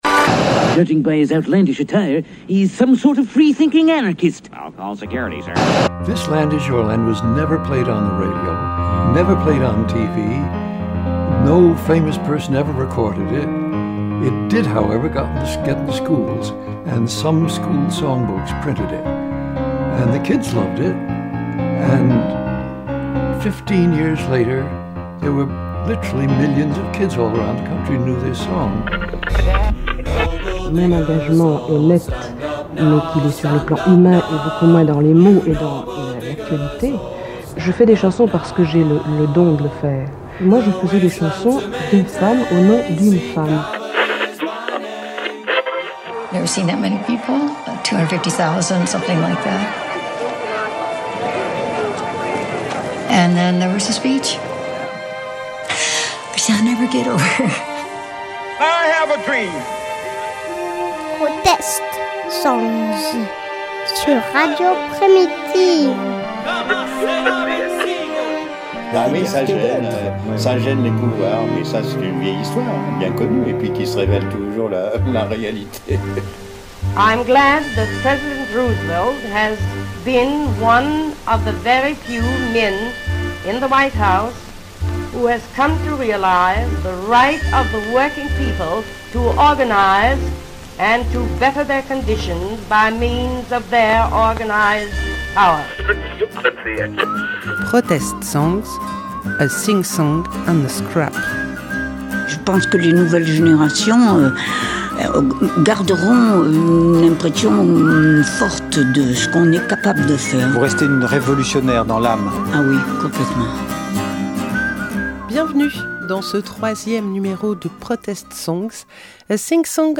🎧 Émission 3 - Protest songs